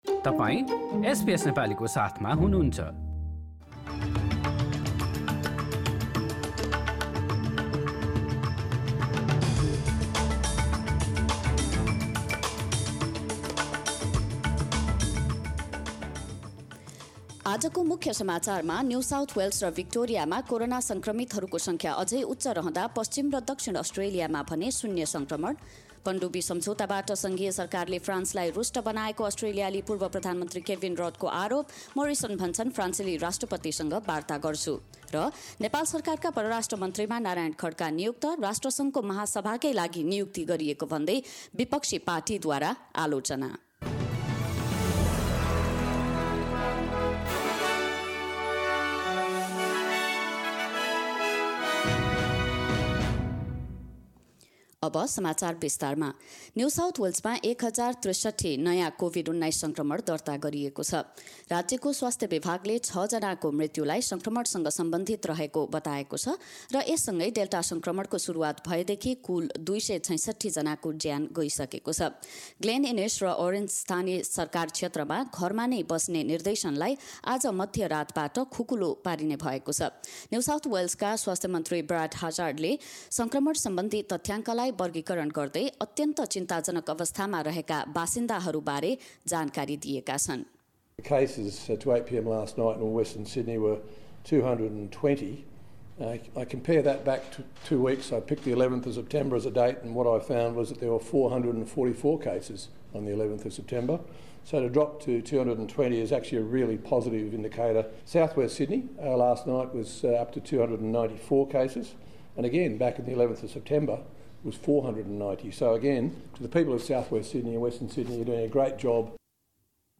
एसबीएस नेपाली अस्ट्रेलिया समाचार: बिहीवार २३ सेप्टेम्बर २०२१